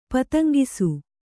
♪ pataŋgisu